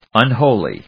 音節un・ho・ly 発音記号・読み方
/`ʌnhóʊli(米国英語), ʌˈnhəʊli:(英国英語)/